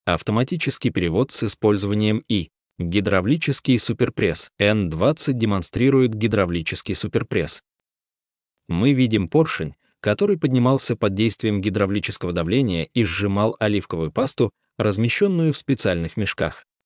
Аудиогид